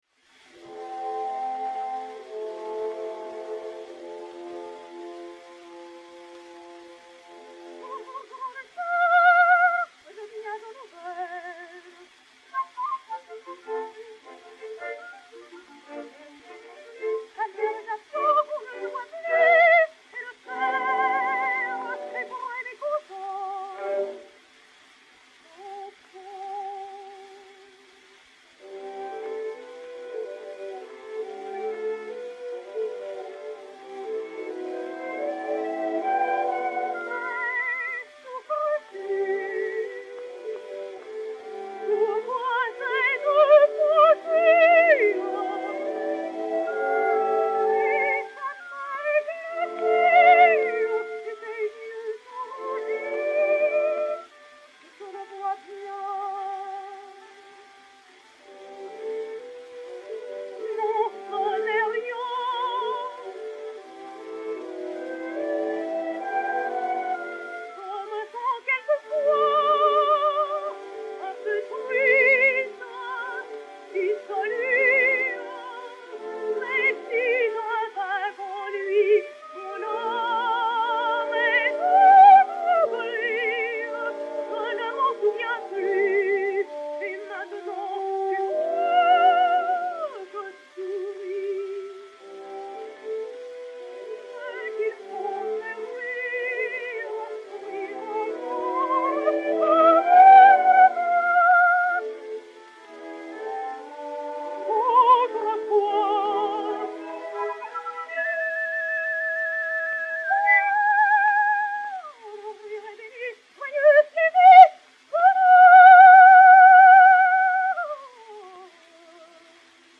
et Orchestre
Disque Pour Gramophone 034070 et 034073, mat. 0908v et 01346v, enr. à Paris le 04 mars 1909